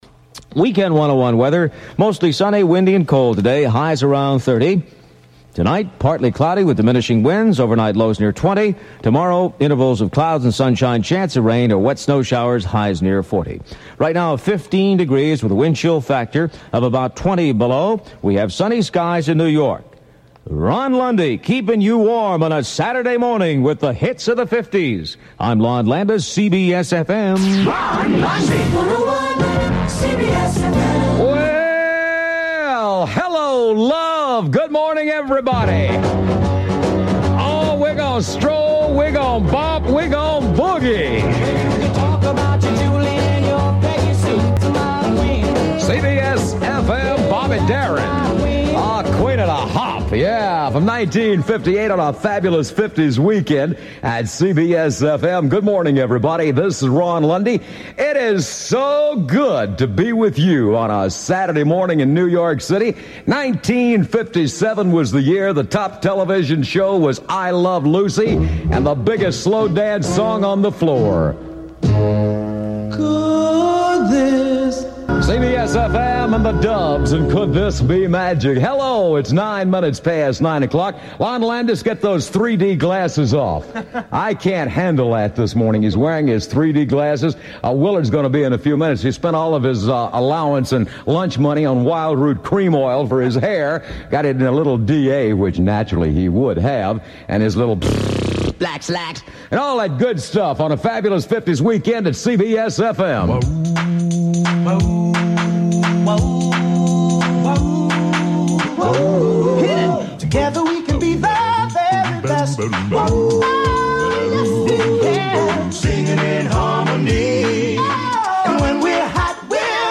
" AIRCHECK OF THE WEEK E dition #1336 Week of March 2, 2025 Talent: RON LUNDY Station: WCBS-FM New Yprk Date: March 8, 1986 Time: 56:51 (unscoped) 23:27 (scoped) Joy was in the air and on the air whenever Ron Lundy spoke into a mic. One of rock radio's most cheerful and upbeat personalities, Lundy was a jock who obviously loved the music and loved being on the air.
wcbsfm-lundy-mar8-86-s.mp3